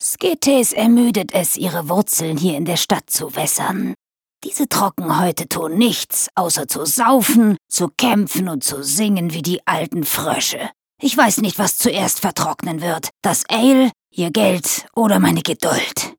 Sprecherin - Sängerin